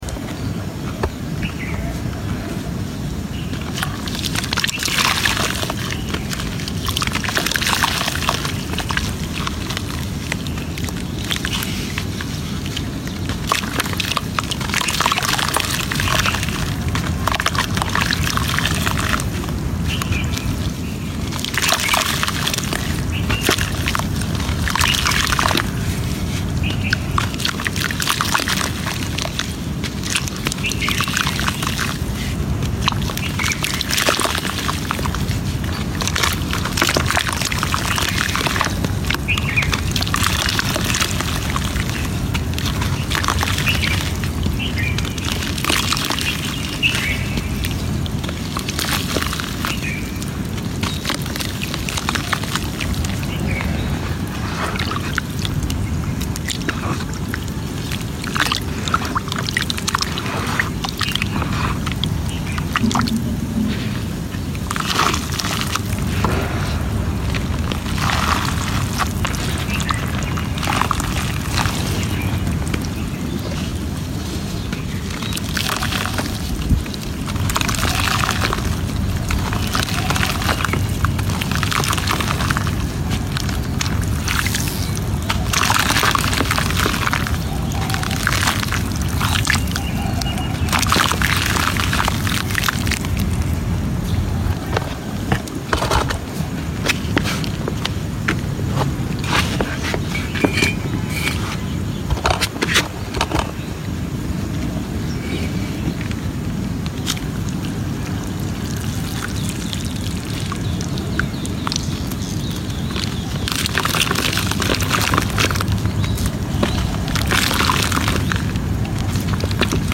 Make This Layered Nutty Crunch Sound Effects Free Download